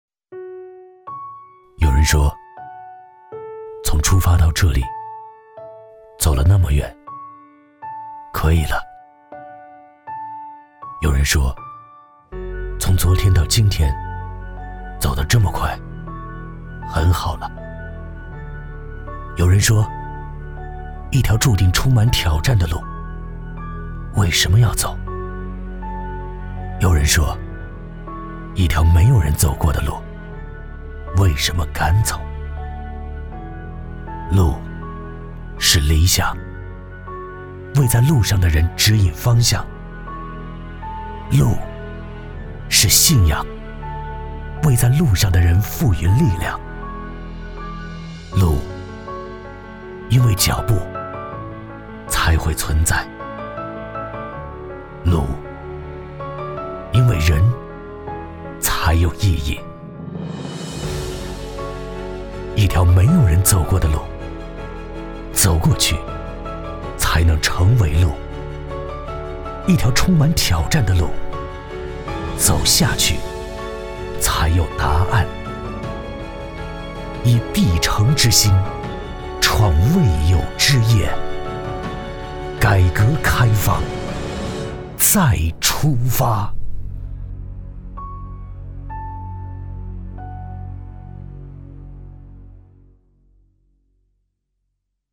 男7号-模仿配音-正式-模仿AI机器人-片段1